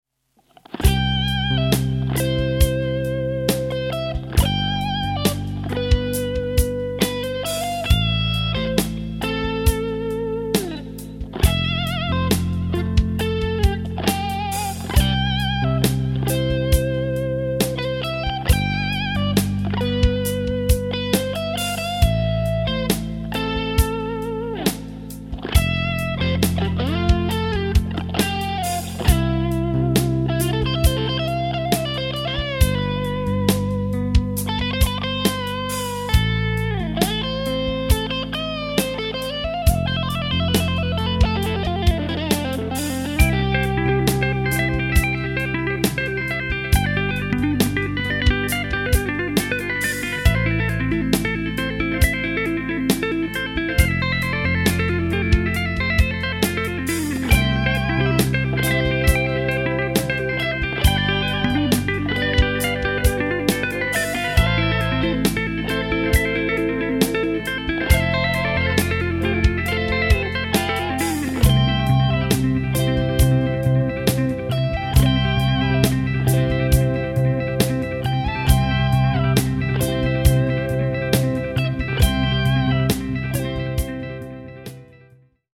[Ambiance]